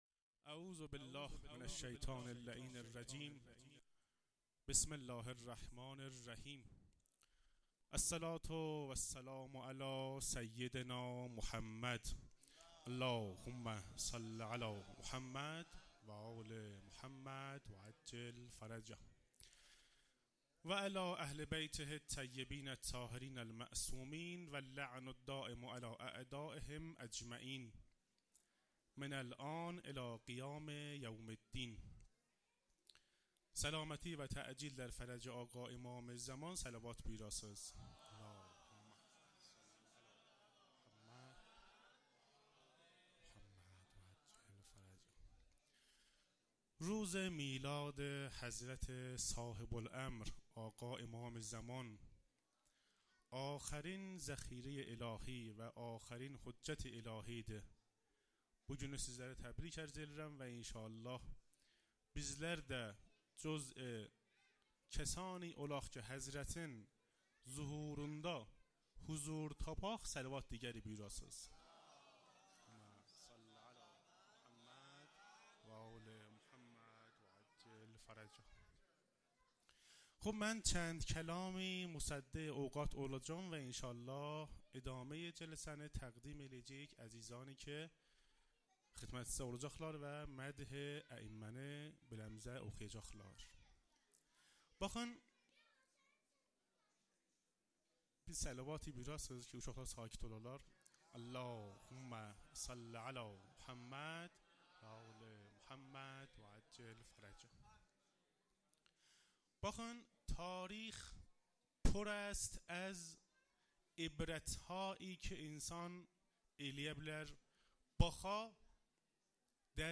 گزارش صوتی جشن نیمه شعبان 1446